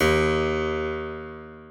piano-sounds-dev
Harpsicord